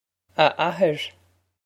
Pronunciation for how to say
Ah a-her
This is an approximate phonetic pronunciation of the phrase.